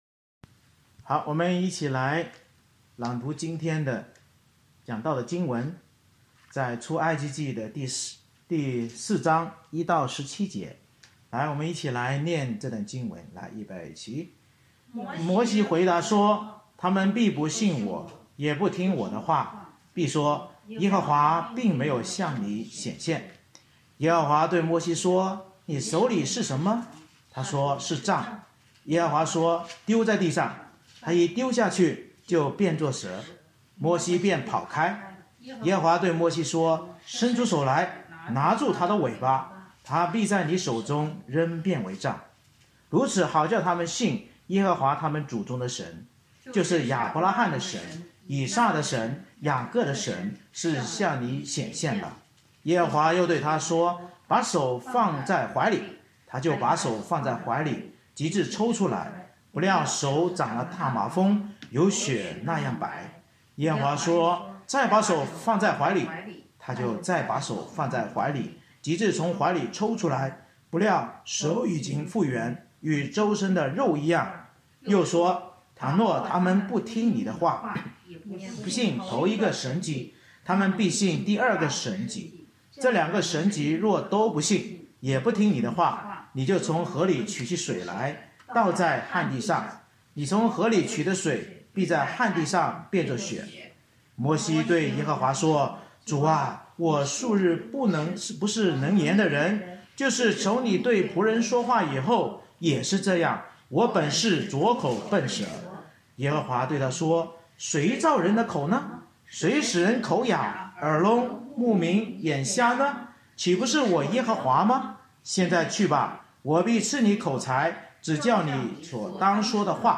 《出埃及记》讲道系列
Service Type: 主日崇拜